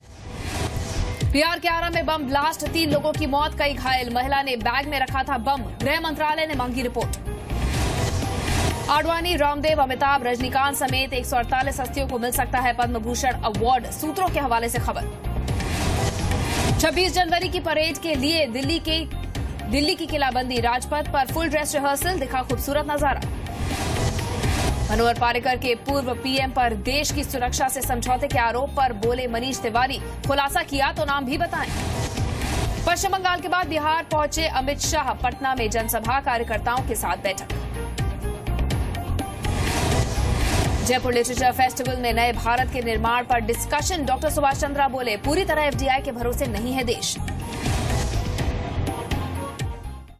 Top news headlines at 1 pm